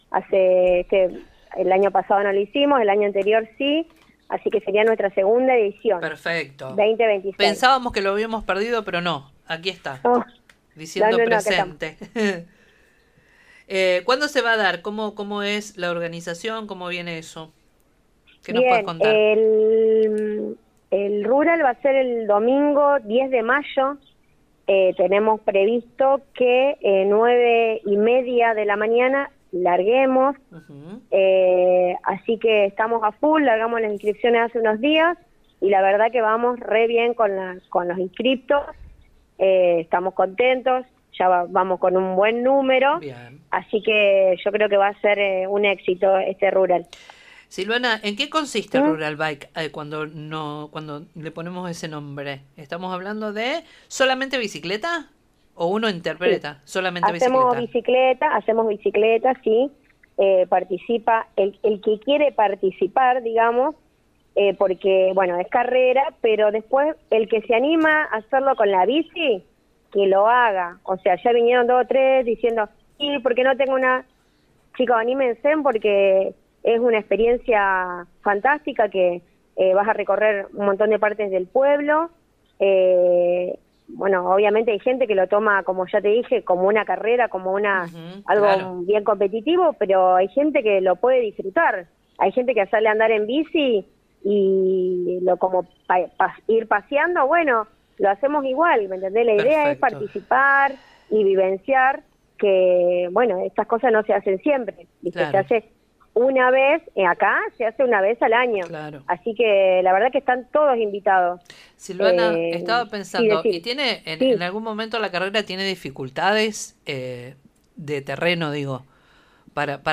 La secretaria de Deportes de Timbúes, Silvana Echegoyenberry, brindó detalles en diálogo con el programa de la radio «Con Voz», sobre la nueva edición del Rural Bike 2026, que tendrá lugar el próximo 10 de mayo desde las 9:30 en el Polideportivo Comunal Francisco “Paco” Cabral.